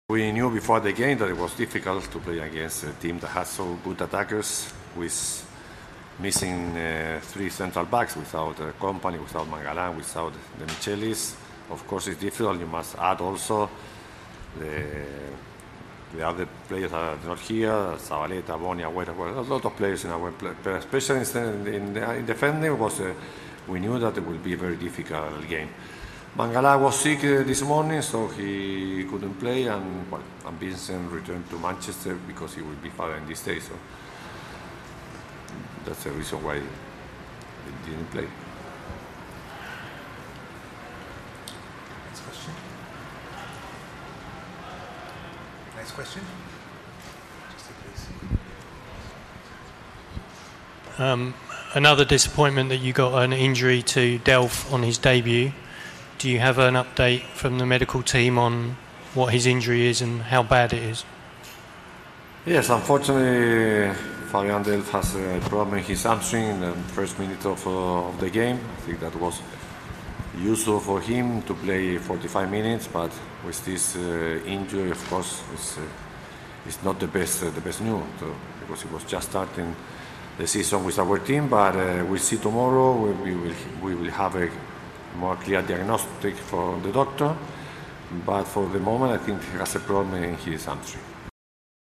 Pellegrini speaks after City lose to Real Madrid 4-1 in friendly.